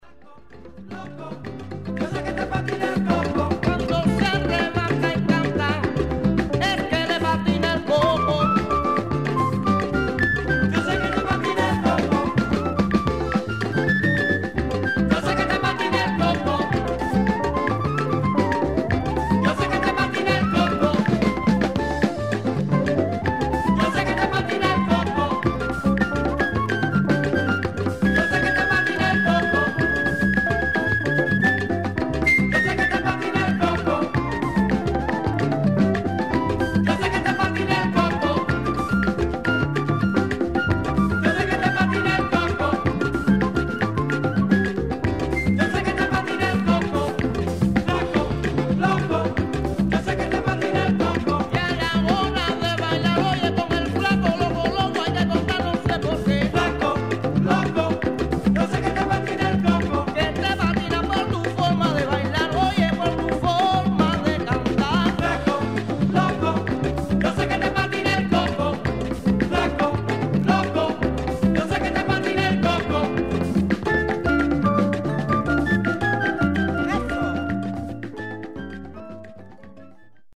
1980年前後の録音。
JAZZ FUSION色の強いアルバムで